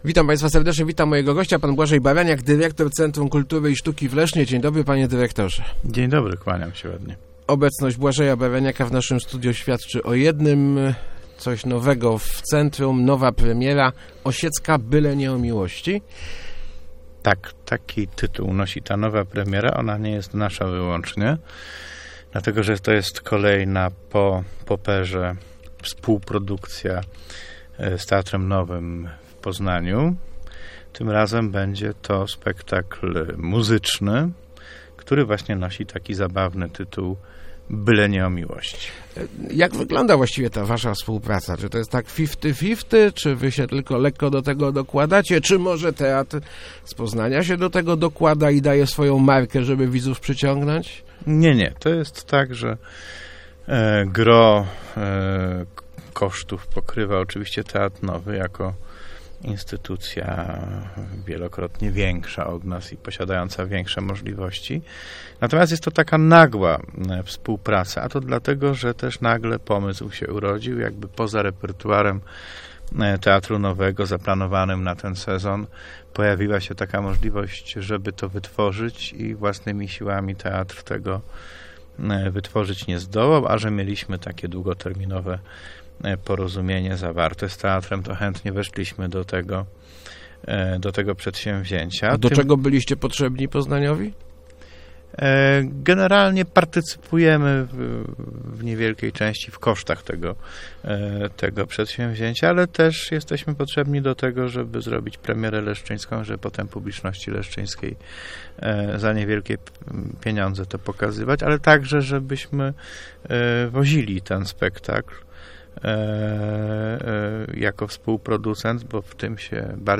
Start arrow Rozmowy Elki arrow Osiecka w CKiS